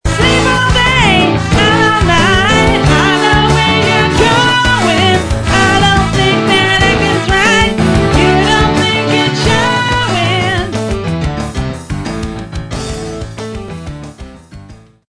Samples Of Cover Tunes With Vocals